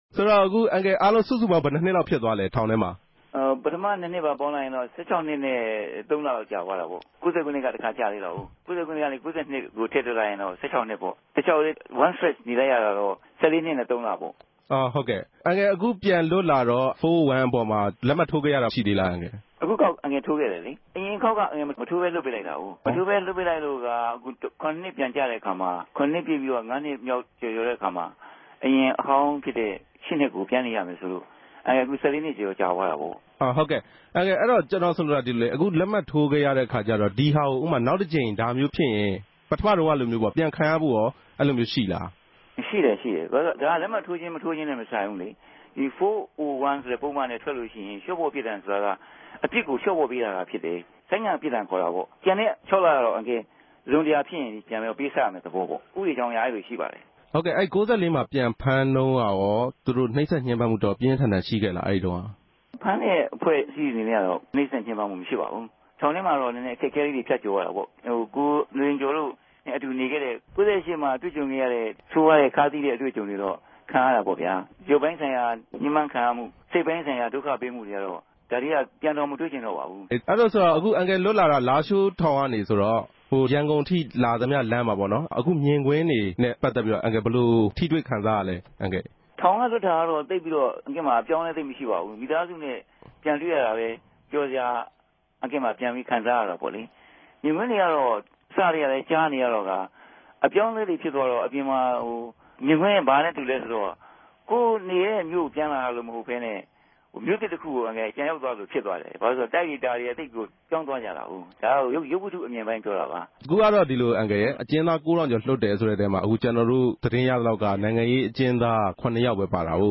ဦးခင်မောင်ဆြေိံြင့် ဆက်သြယ်မေးူမန်းခဵက်။